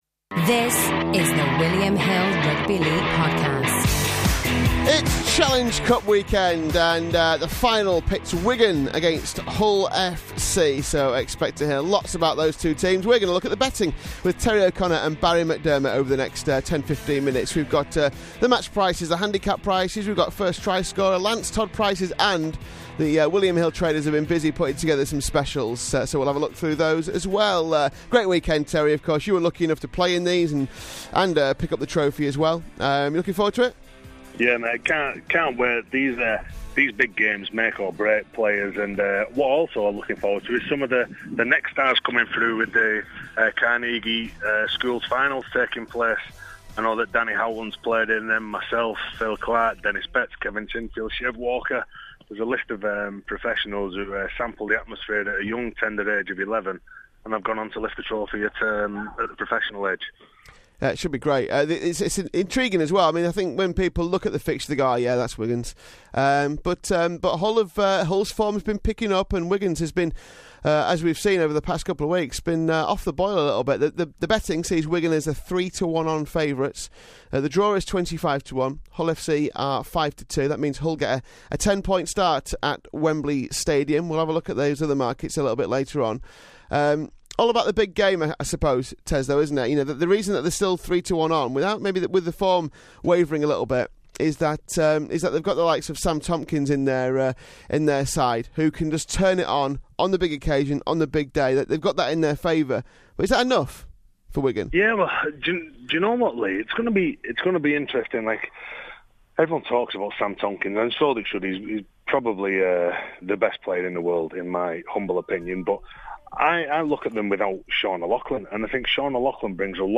Two men who won the Challenge Cup preview this weekend's showpiece occasion for William Hill.